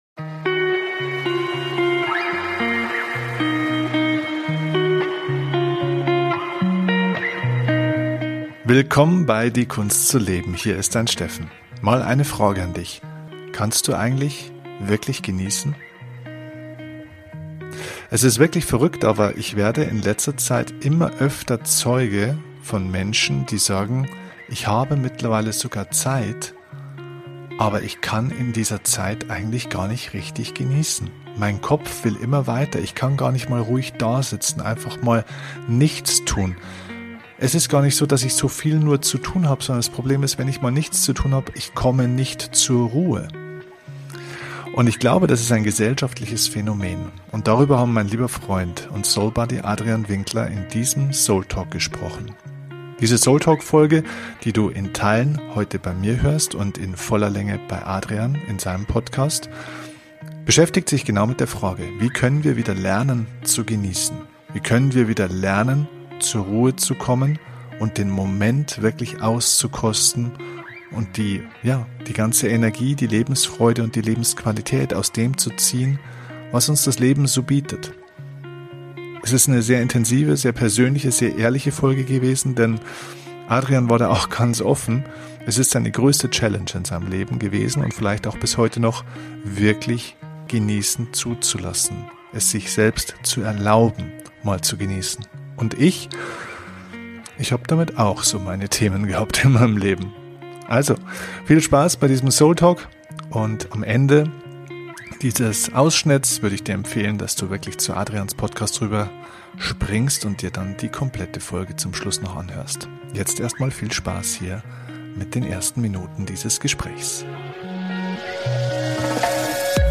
Wie immer bei diesem Format: Es gibt kein Skript, nur Gedanken zweier Freunde.